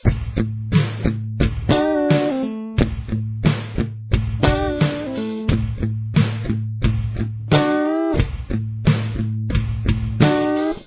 Here are 3 riffs from the intro.
Guitar 3